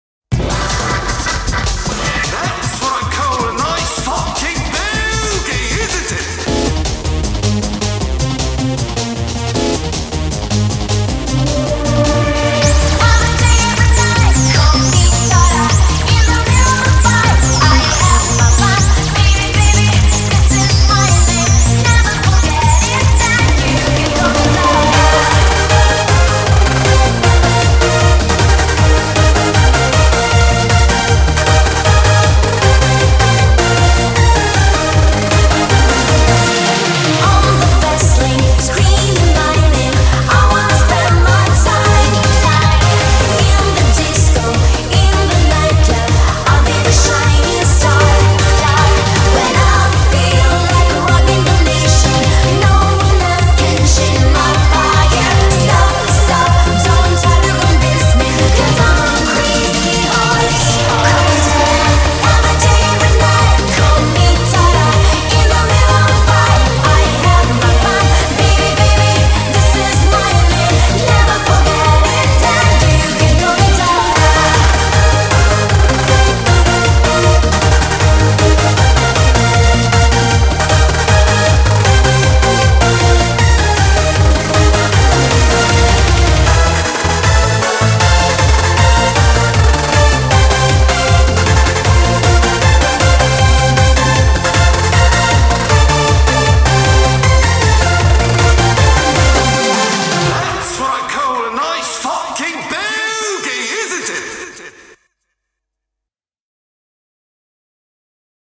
BPM156
Audio QualityPerfect (High Quality)
BPM: 156